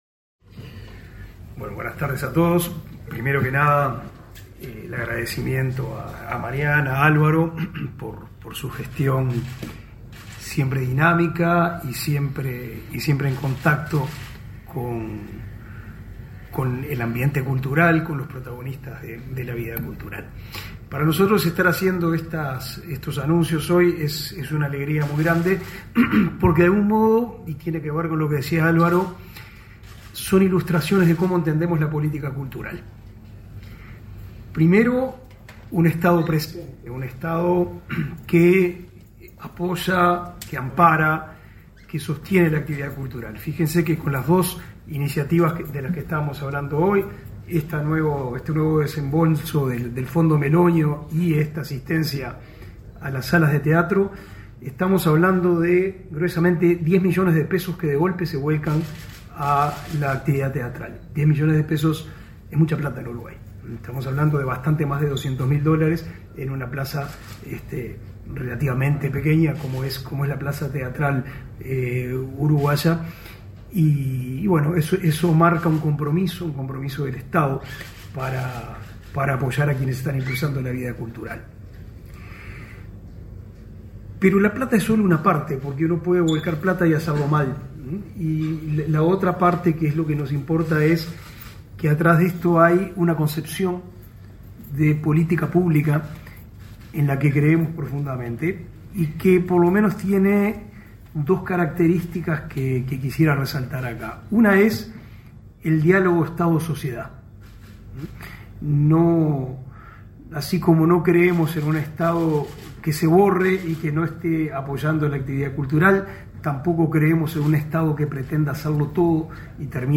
Conferencia de prensa de autoridades del MEC para apoyar a instituciones culturales afectadas por la emergencia climática
Conferencia de prensa de autoridades del MEC para apoyar a instituciones culturales afectadas por la emergencia climática 07/03/2022 Compartir Facebook X Copiar enlace WhatsApp LinkedIn Este 7 de marzo, las autoridades del Ministerio de Educación y Cultura (MEC) realizaron una conferencia de prensa para anunciar el apoyo a nueve de las once instituciones afectadas por la emergencia climática para recuperar sus instalaciones. Participaron el ministro Pablo da Silveira; la directora de Cultura, Mariana Wainstein, y el coordinador del Instituto Nacional de Artes Escénicas, Álvaro Ahuchain.